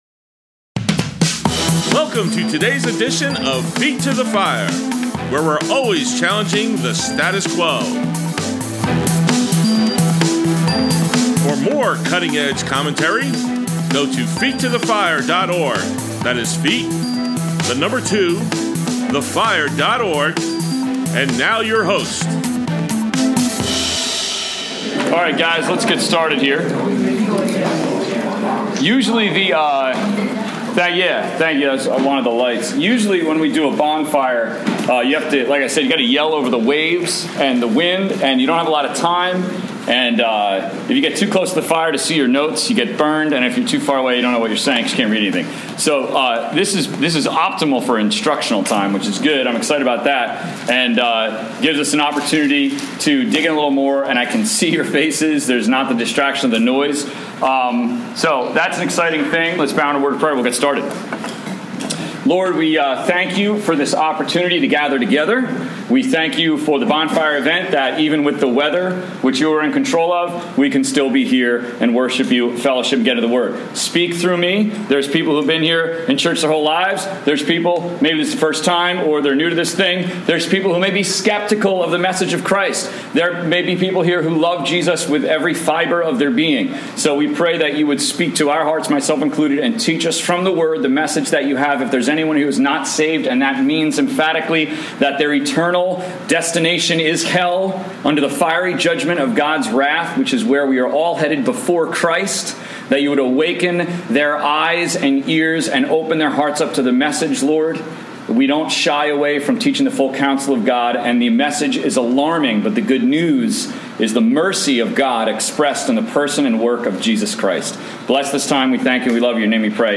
Join our Youth Group Bonfire event as we take a look into the Scriptures…